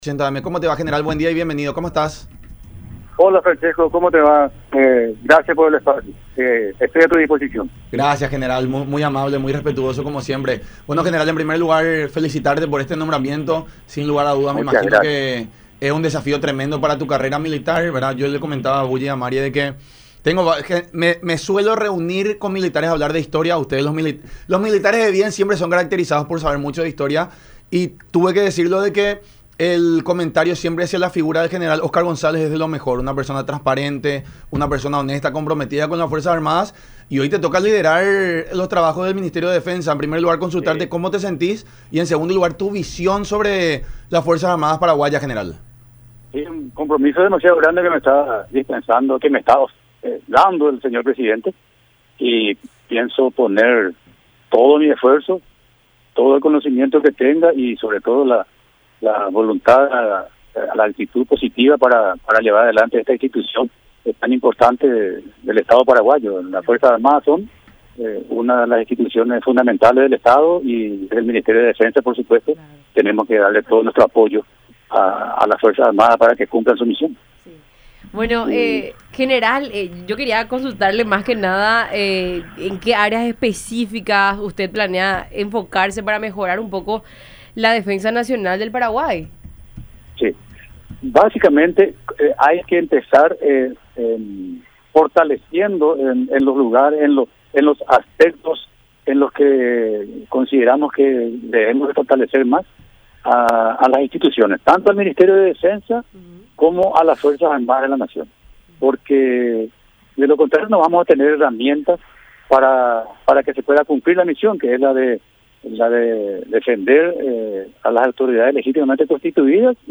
Tenemos que darle todo nuestro apoyo a las Fuerzas Armadas para que cumpla su misión, que es defender a las autoridades legítimamente constituidas y proteger la integridad territorial de nuestro país”, dijo González en diálogo con La Unión Hace La Fuerza a través de Unión TV y radio La Unión.